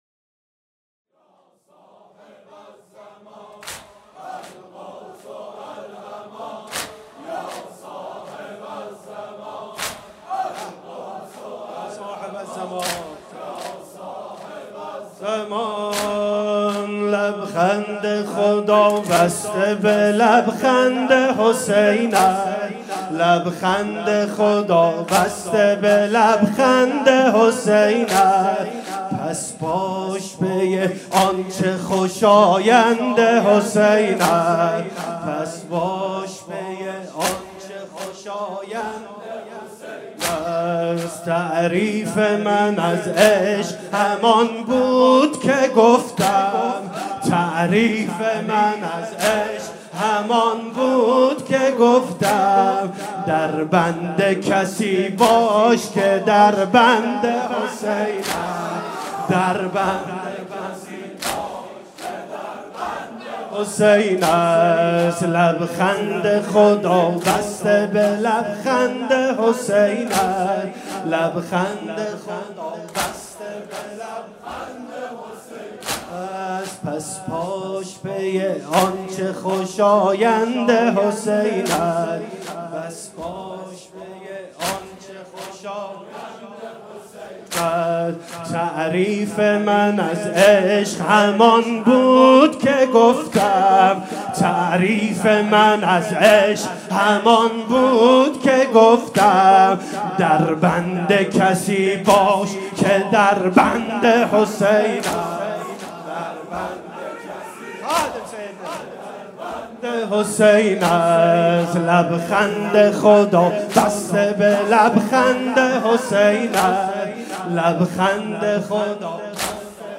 شب اول محرم 97 - لبخند خدا بسته به لبخند حسین است